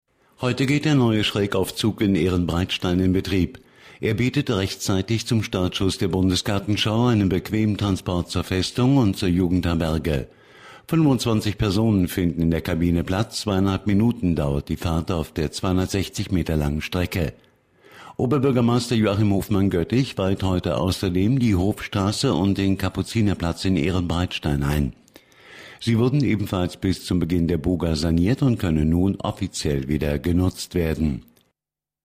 Antenne Koblenz 98,0, Nachrichten 9.30 Uhr, 12.04.2011